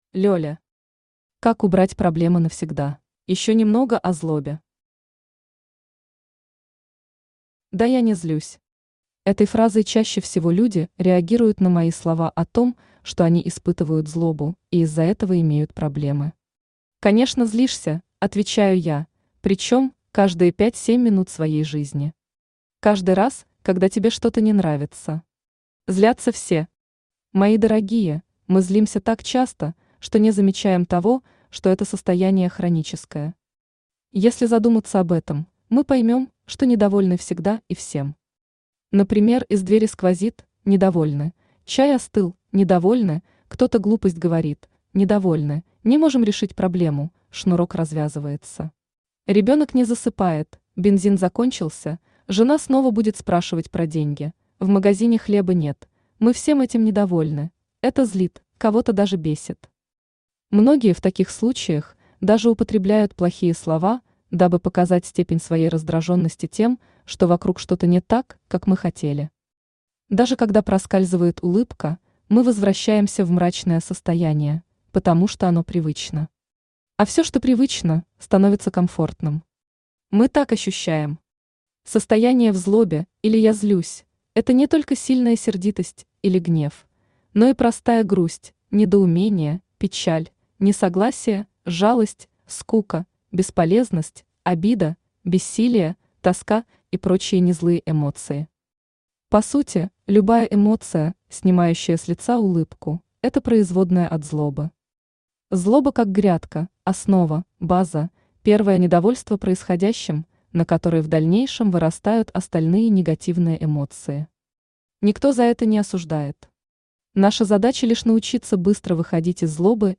Аудиокнига Как убрать проблемы навсегда | Библиотека аудиокниг
Aудиокнига Как убрать проблемы навсегда Автор Лёля Читает аудиокнигу Авточтец ЛитРес.